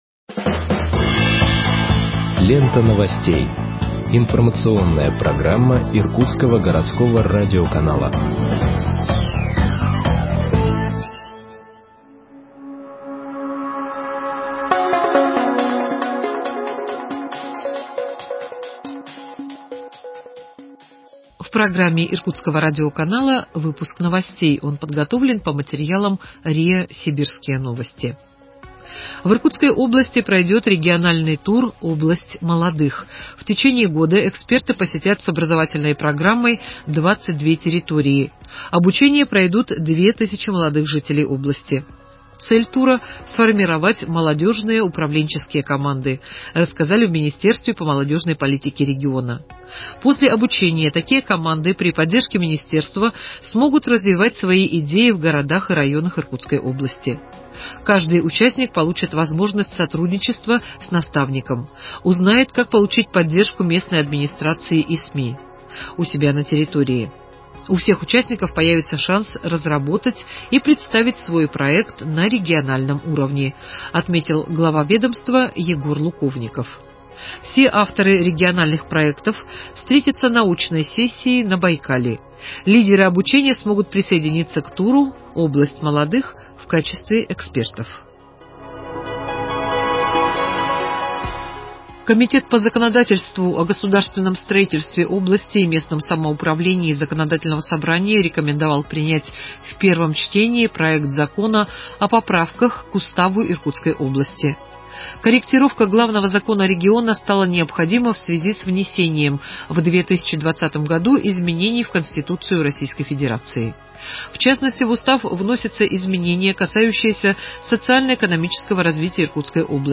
Выпуск новостей в подкастах газеты Иркутск от 21.01.2021 № 2